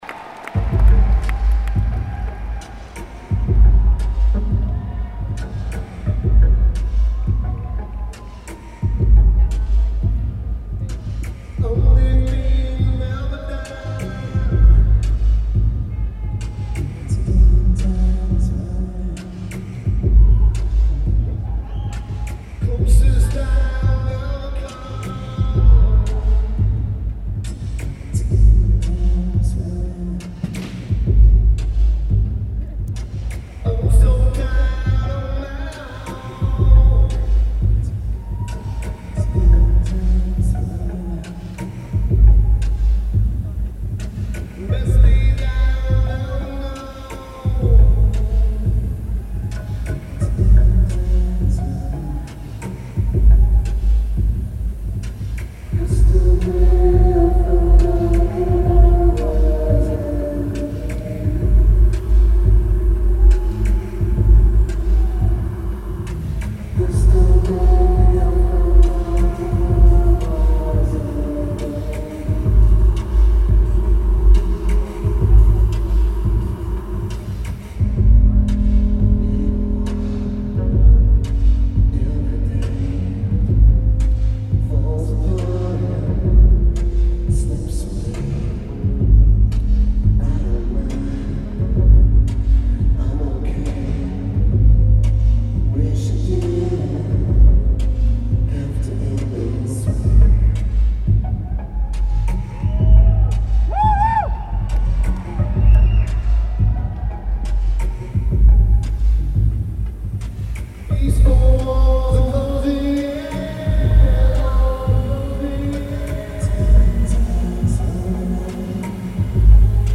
Barclays Center
Brooklyn, NY United States
Lineage: Audio - AUD (Built-In Mics + Tascam DR-2D)